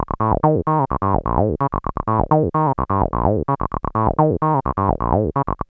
BASS_L_5.wav